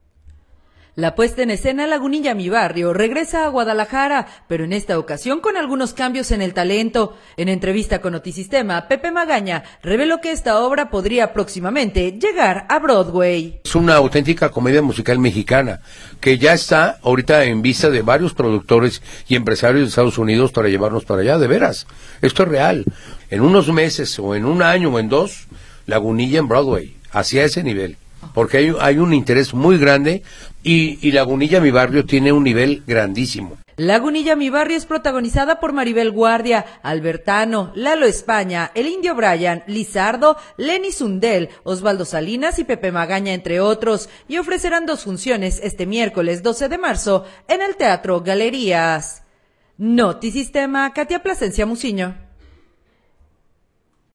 La puesta en escena Lagunilla mi barrio regresa a Guadalajara, pero en esta ocasión con algunos cambios en el talento. En entrevista con Notisistema